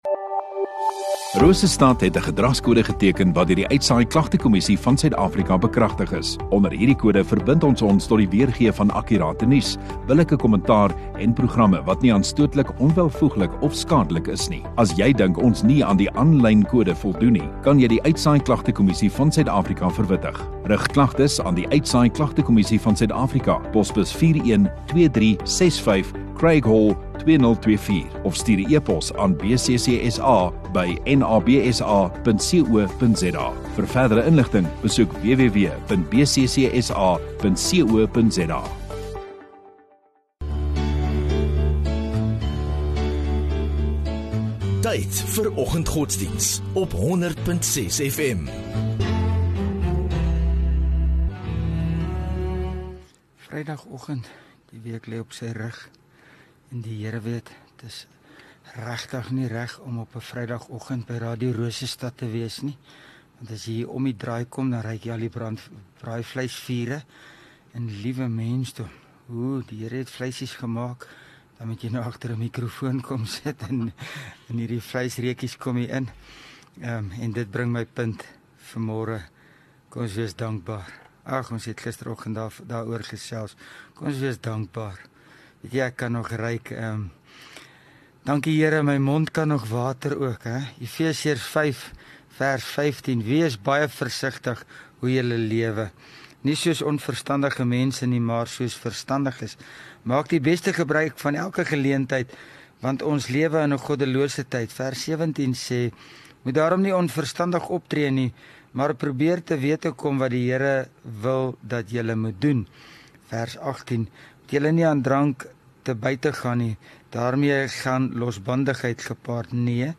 Rosestad Godsdiens 21 Nov Vrydag Oggenddiens